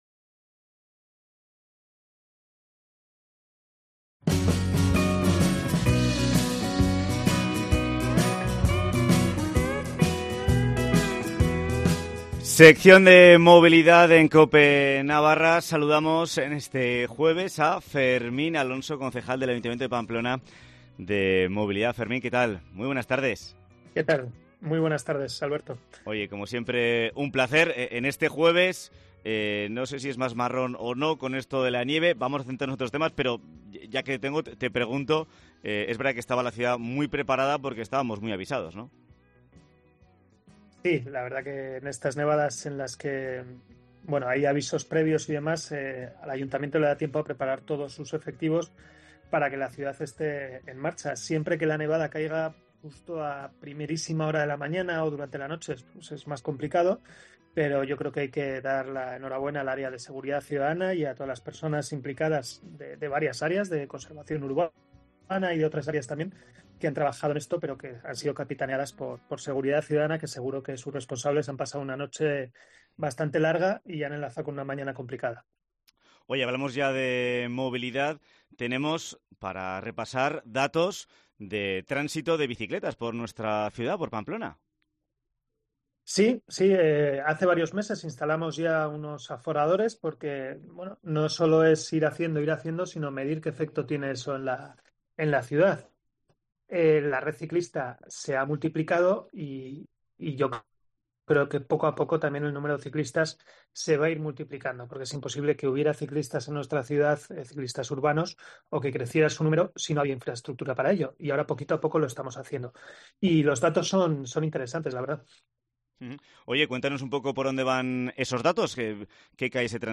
Sección de movilidad